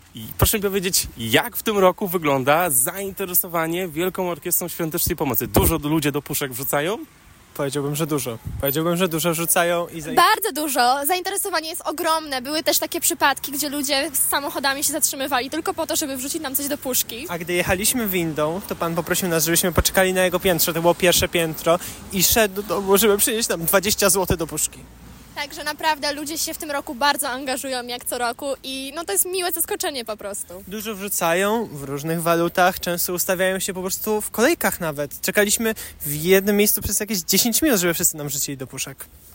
Ludzie w Świnoujściu potrafią zatrzymywać się samochodami, aby wesprzeć WOŚP, wracają do domów po gotówkę i obdarowują Orkiestrę różnymi walutami – relacjonują świnoujscy wolontariusze.